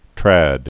(trăd)